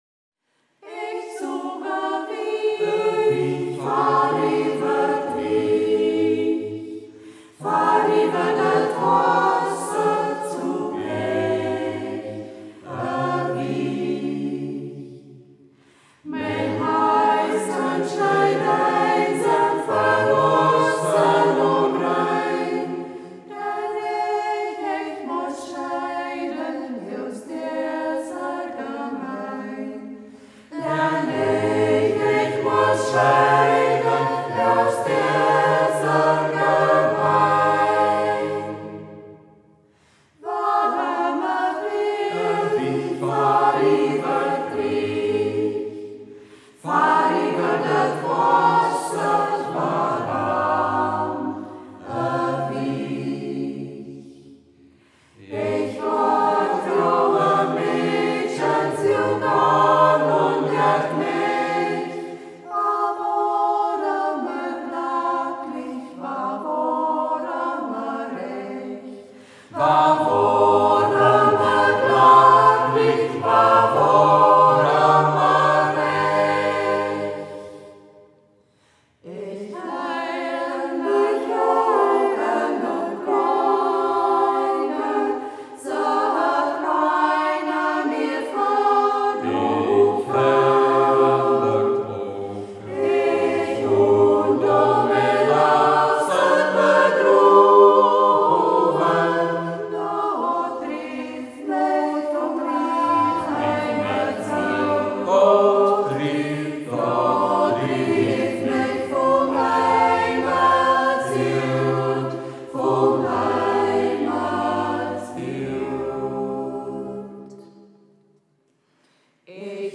Ortsmundart: Braller